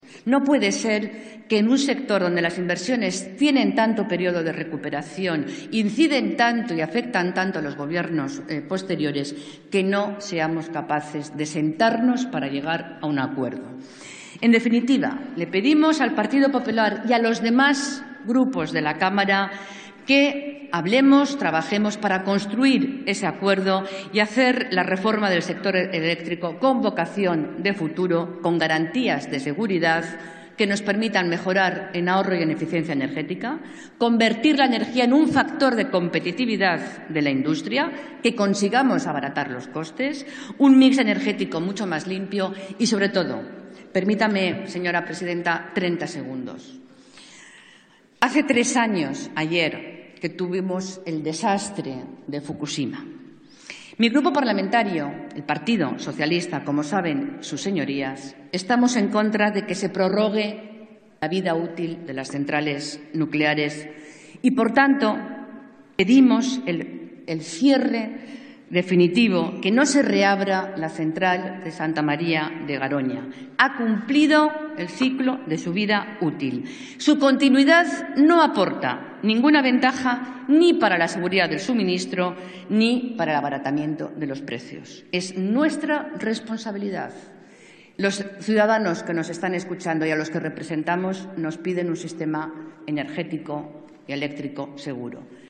Fragmento de la intervención de Inmaculada Rodríguez Piñero defendiendo una moción en la que exige una sistema de precios de la energía estable y que beneficie al ciudadano 12/03/2014